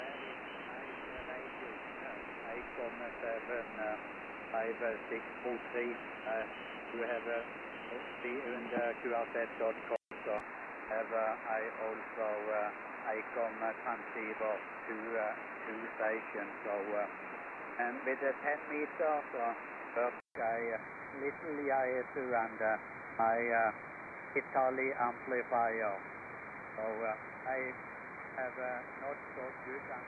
Second 00-10 > JRC NRD 545 DSP
Second 10-20 > Icom IC-R8600
Second 20-30 > Winradio G33DDC Excalibur Pro
All three radios produce a clear signal with their individual characteristics.